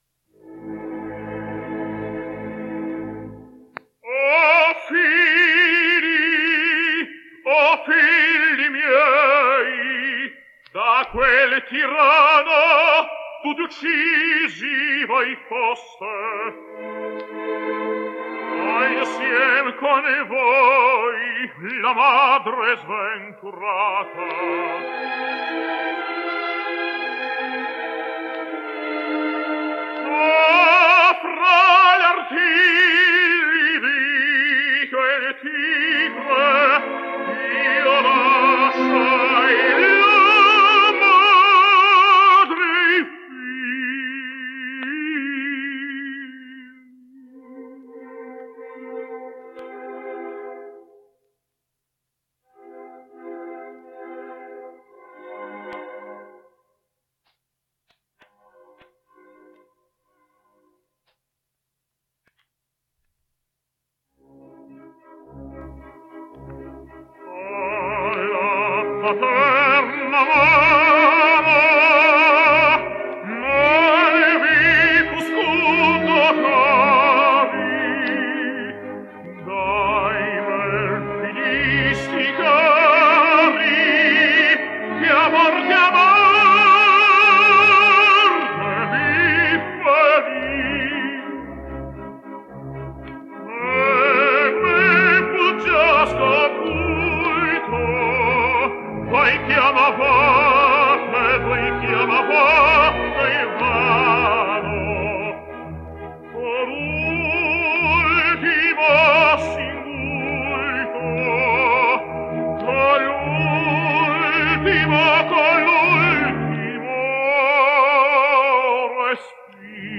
Romanian Tenor
Ah La Paterna Mano / Macbeth / 1977 – Ludovic Spiess